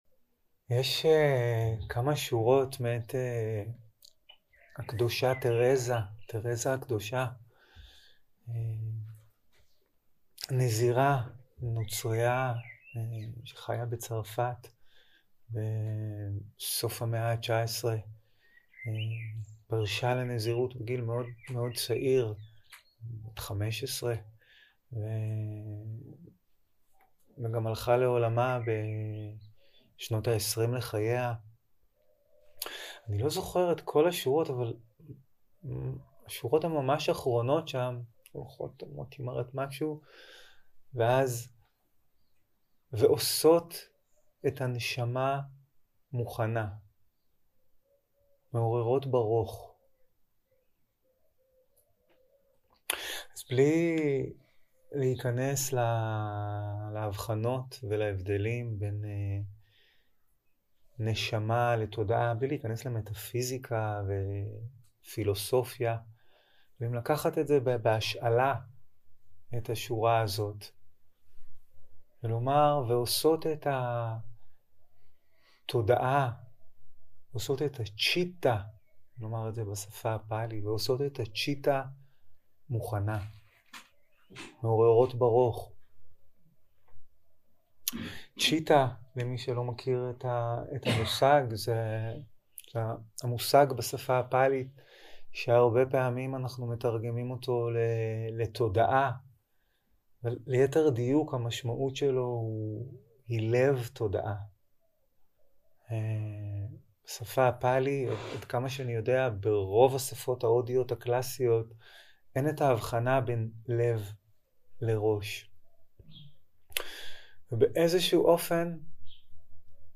יום 2 - הקלטה 1 - בוקר - הנחיות למדיטציה - הכנת הלב והתודעה 0:00 0:00 סוג ההקלטה: Dharma type: Guided meditation שפת ההקלטה: Dharma talk language: Hebrew